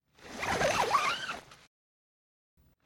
Шум замка у палатки в походе